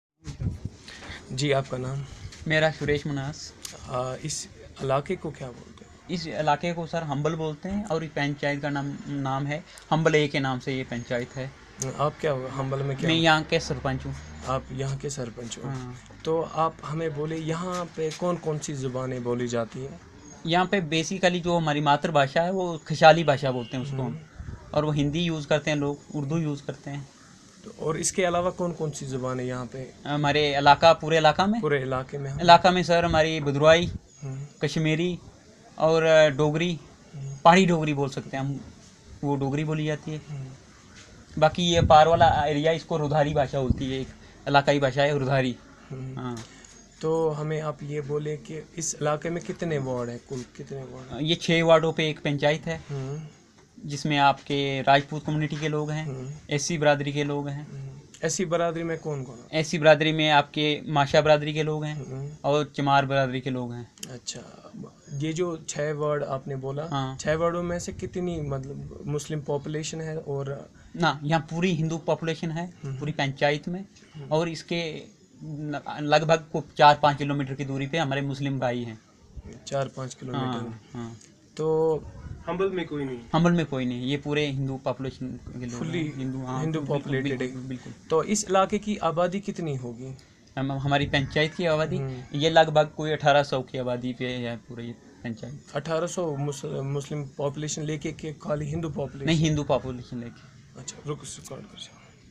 Narrative about the language and culture of the natives